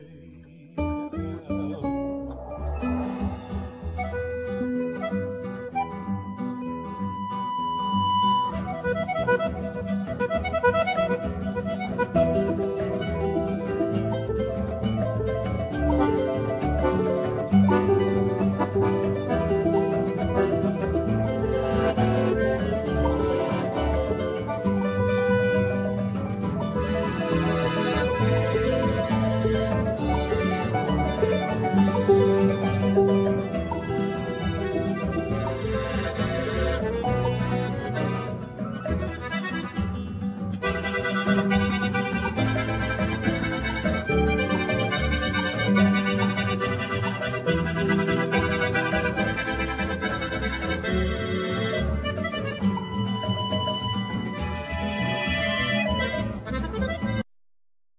Accordion,Vocal,Percussions
Harp,Percussions
Bandoneon
Guitar
Double bass